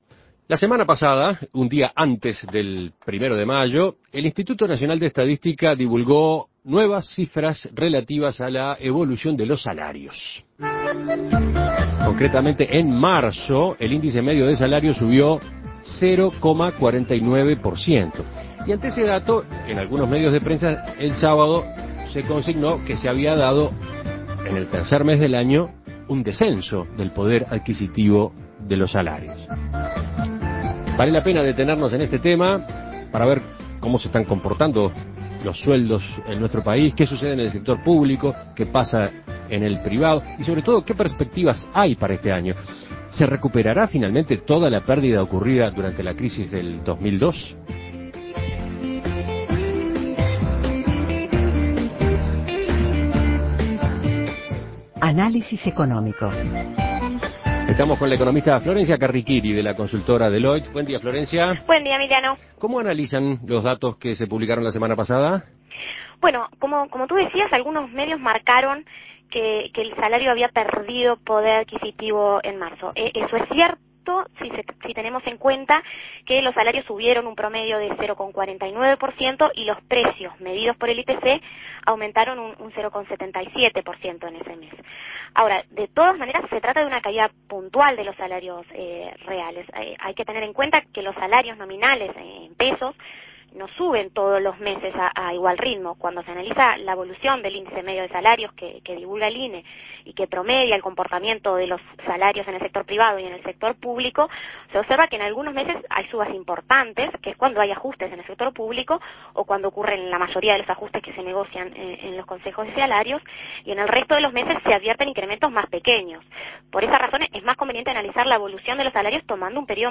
Análisis Económico ¿Cómo se están comportando los salarios en 2009?